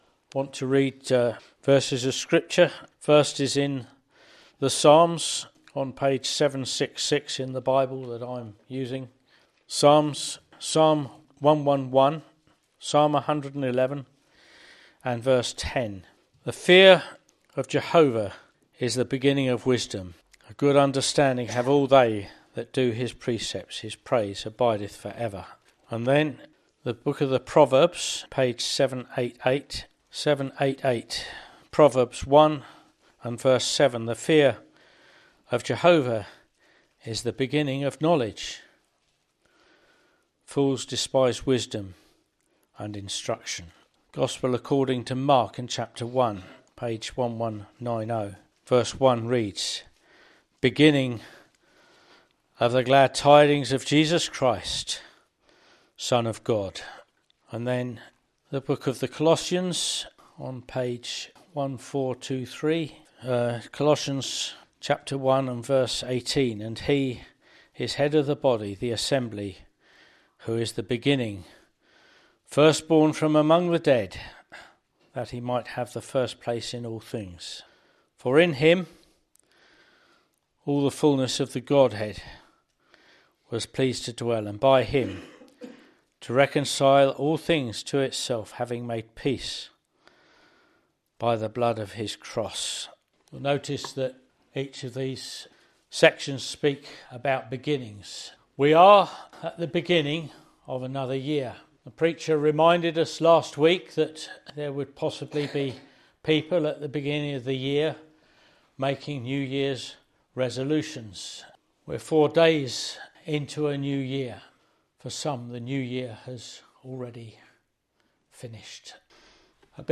This preaching points us to the greatest new beginning of all — the gospel of Jesus Christ — where God offers forgiveness, new life, and a fresh start through the cross and resurrection, calling us to begin this year in faith and obedience to Him.